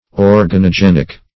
Organogenic \Or`ga*no*gen"ic\